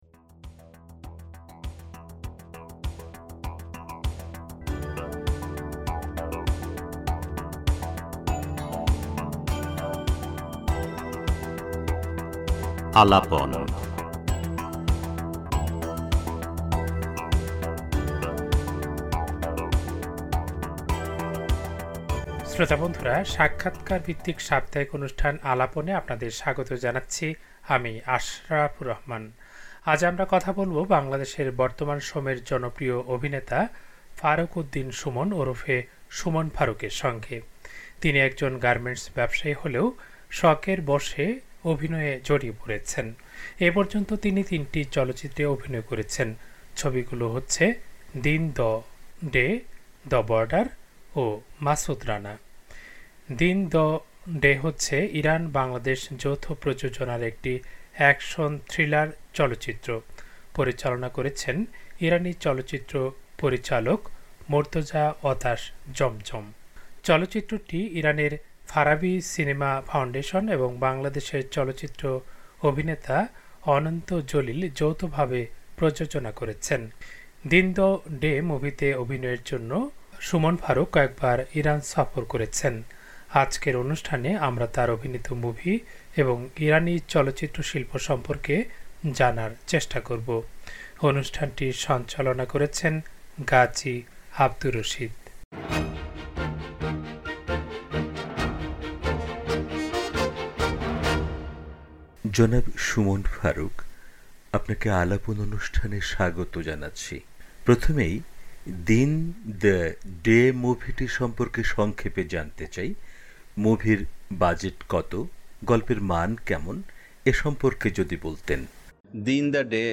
সাক্ষাৎকারভিত্তিক সাপ্তাহিক অনুষ্ঠান